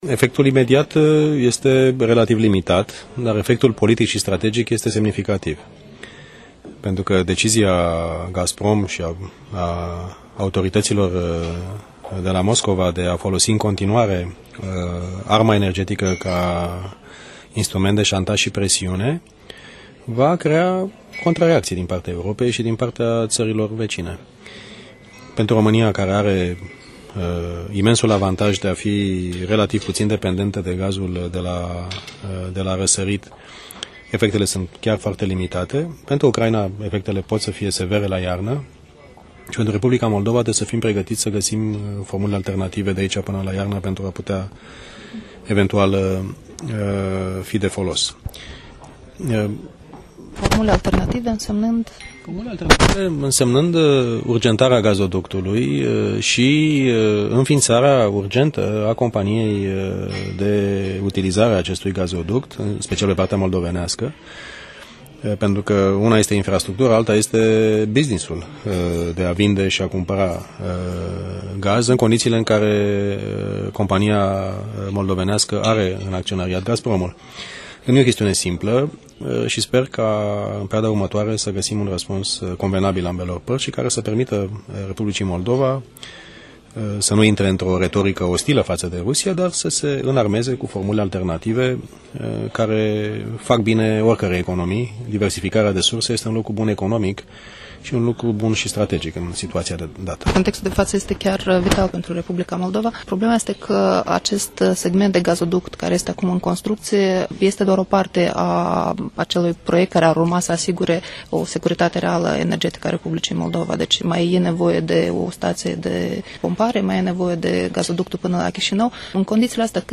Interviul acordat Europei Libere la Bălți de Mircea Geoană